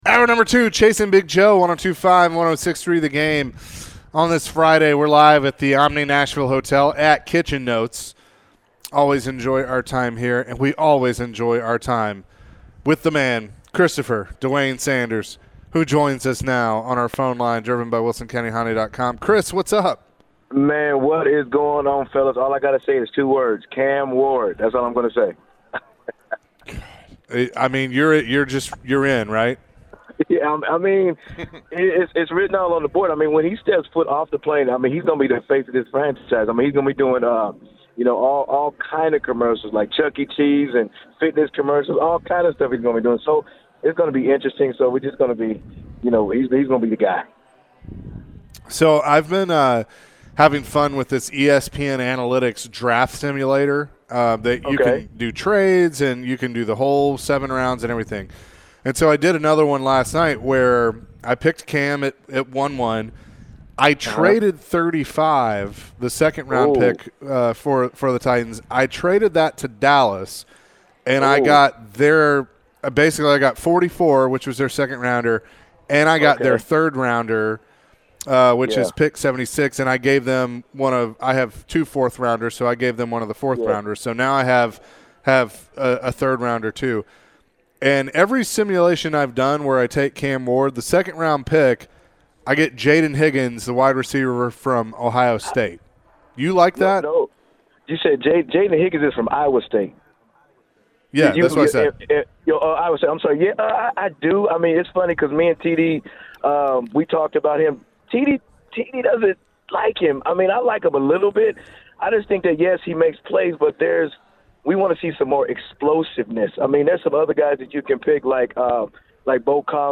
Forever Titans WR Chris Sanders joined the show and was asked about the upcoming NFL draft and some prospects that might interest the Titans. Later, Chris went through a mock draft with the guys.